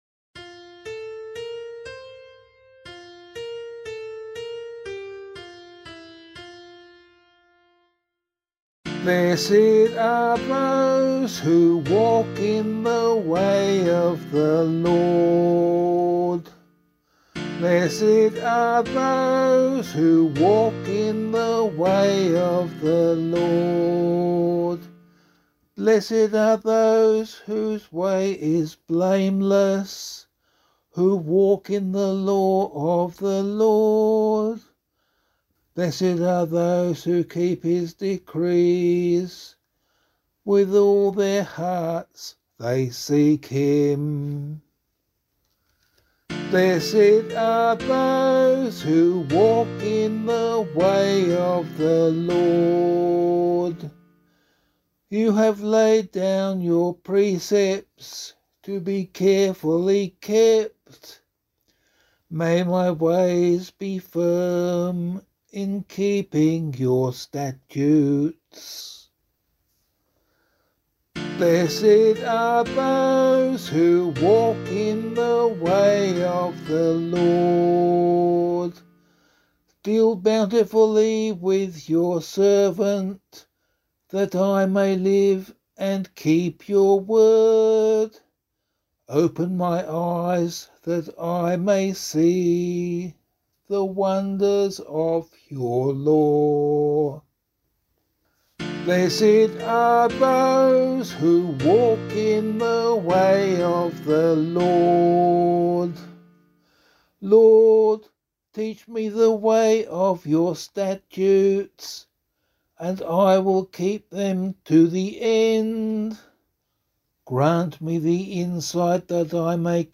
040 Ordinary Time 6 Psalm A [APC - LiturgyShare + Meinrad 1] - vocal.mp3